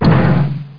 00865_Sound_TowerHit.mp3